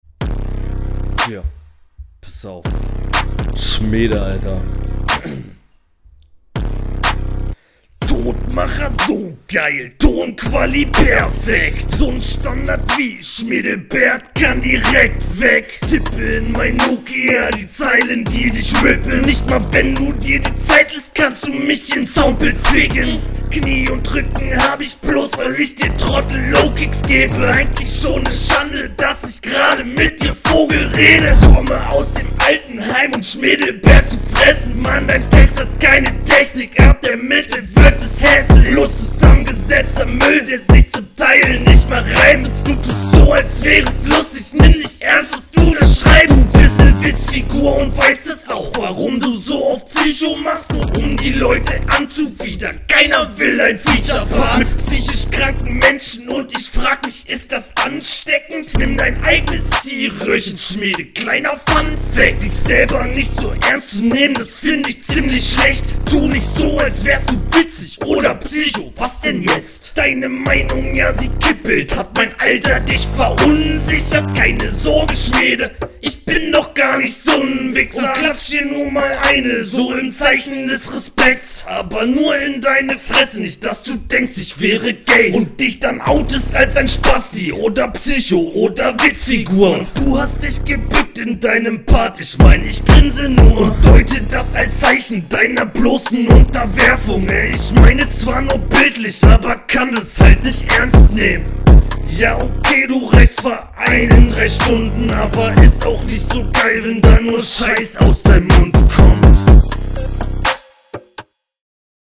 Flow ist im Vergleich zum Gegner gar nicht so geil.
Jaa der Stimmeneinsatz hier ist natürlich etwas meh, kommst halt nicht so gut auf den …